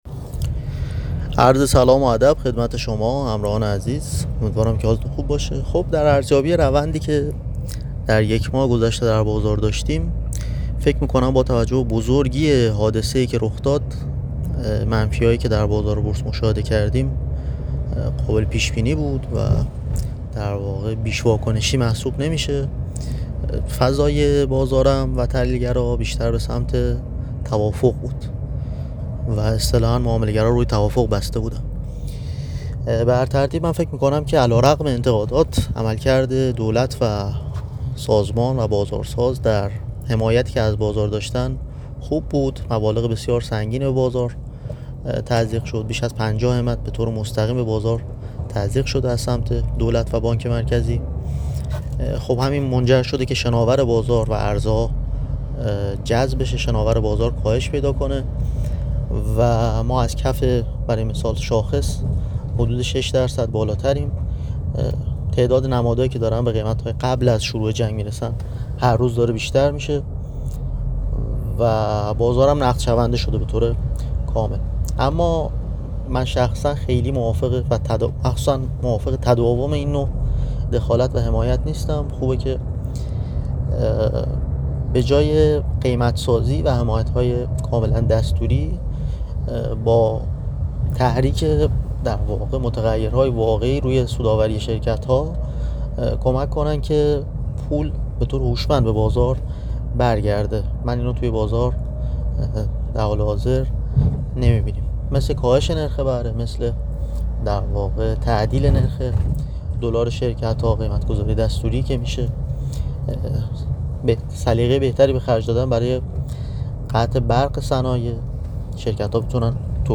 کارشناس بازارهای مالی در گفتگو با بازار عنوان کرد؛